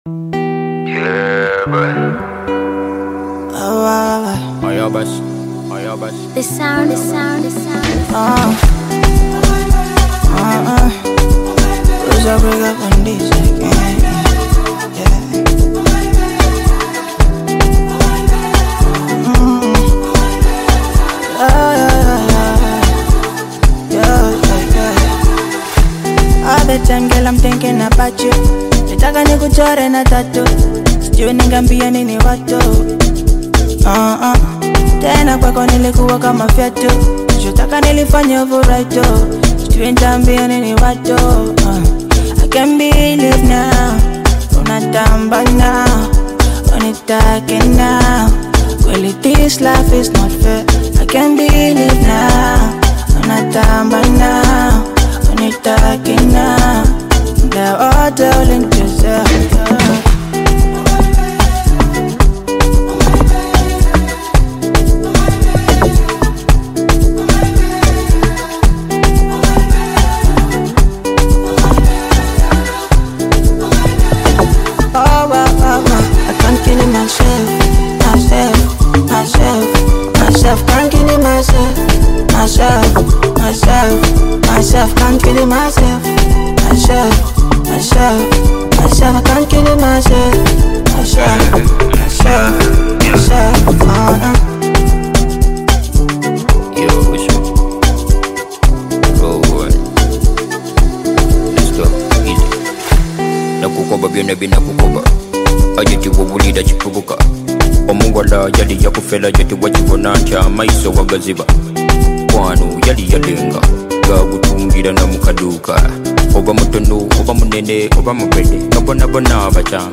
Tanzanian Afrobeat and Bongo Flava
Through heartfelt verses and a catchy chorus